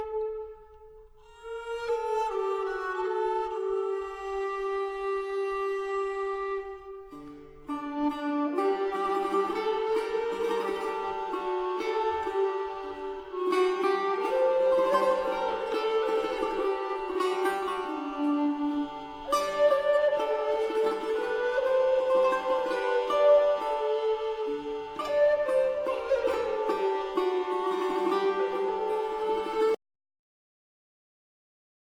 Musique d'Espagne juive et chrétienne